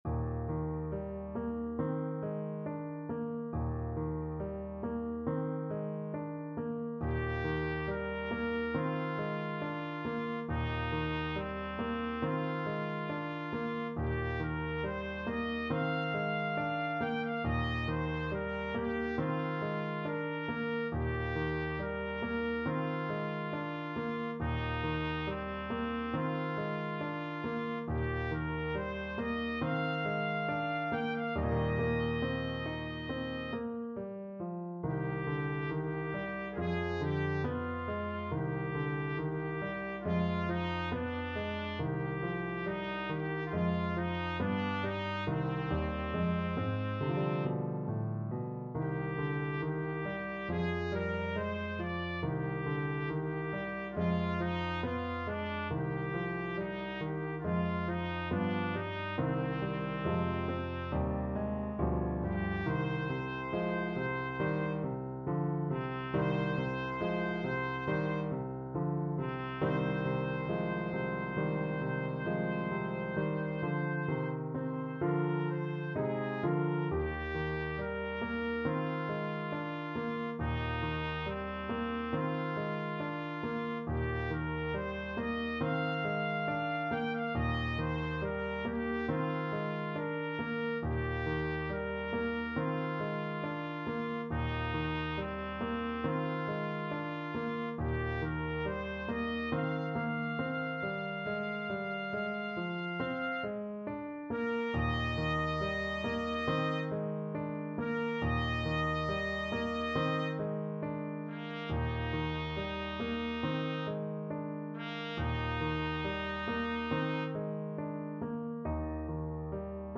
Trumpet
4/4 (View more 4/4 Music)
Eb major (Sounding Pitch) F major (Trumpet in Bb) (View more Eb major Music for Trumpet )
~ =69 Poco andante
Classical (View more Classical Trumpet Music)
ilyinsky_op13_7_berceuse_TPT.mp3